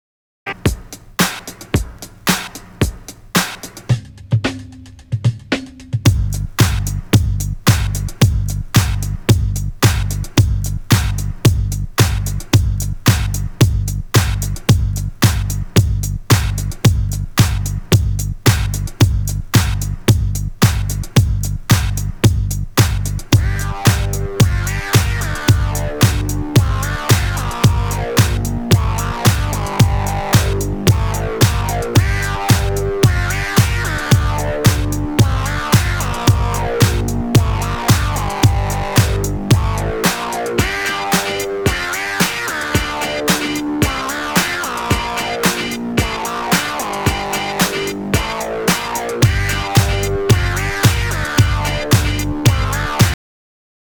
j'ai essayé de jouer le meme plan, avec la meme intensité
c'est subtil mais on perçoit comme un voile sur le plan numero 1 , et surtout, les notes sont moins "ensemble", c'est surtout une perception qu'on a en jouant ...et vraiment ca fait une enorme difference pour moi, meme si à l'ecoute c'est effectivement tres faible
(pour info c'est le multivalve en post ,puis pre hush )